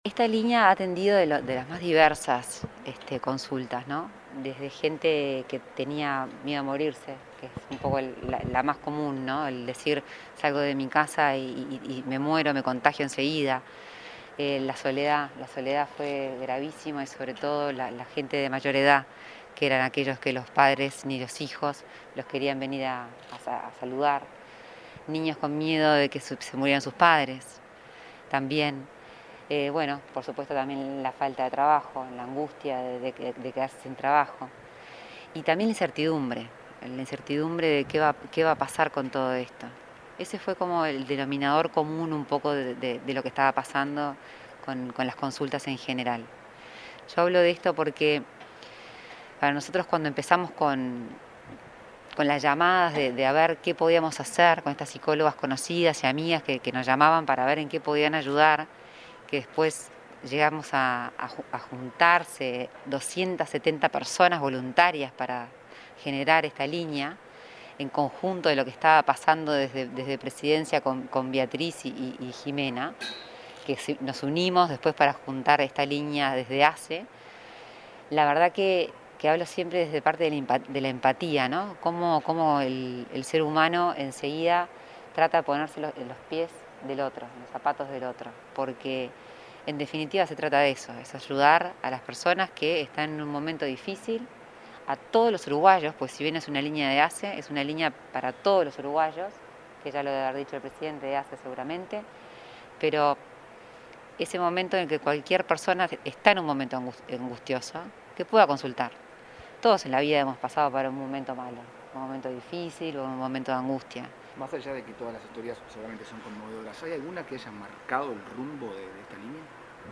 Declaraciones de Lorena Ponce de León